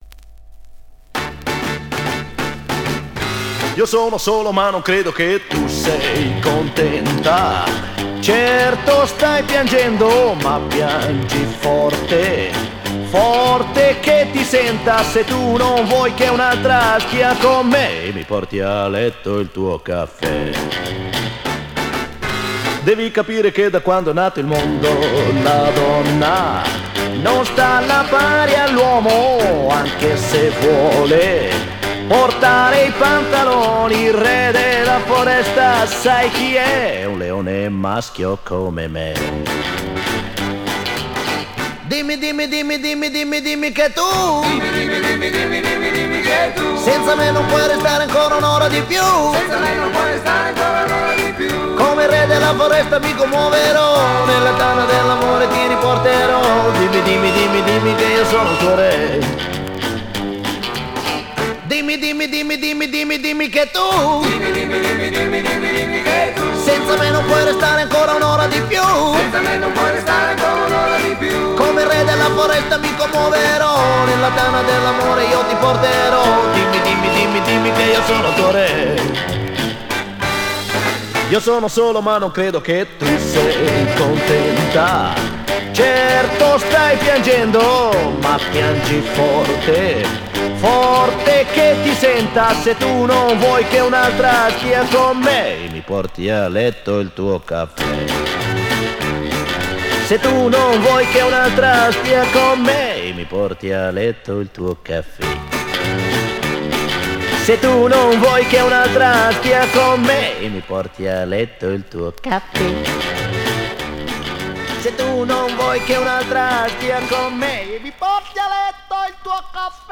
Жанр: Rock, Funk / Soul, Pop
Стиль: Rock & Roll, Chanson, Pop Rock, Ballad